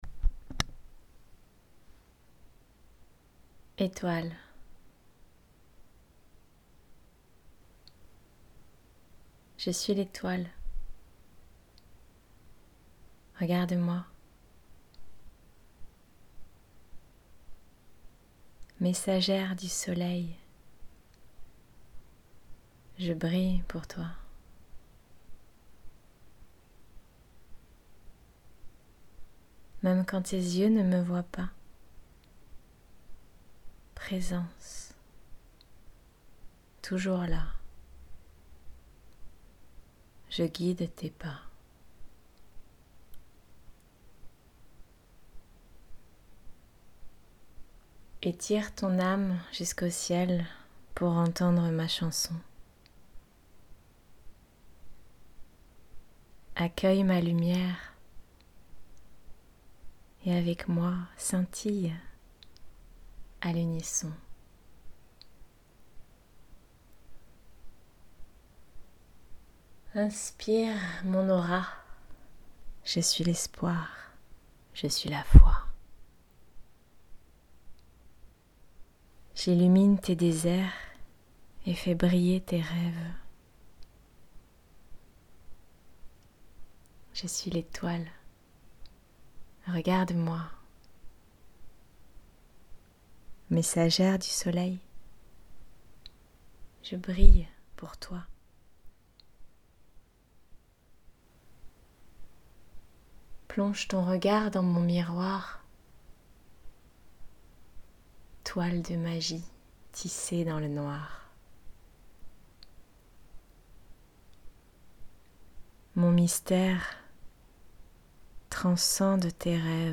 🌟 Etoile 🌟 Poème à écouter avec la Synergie Olfactive "Etoile".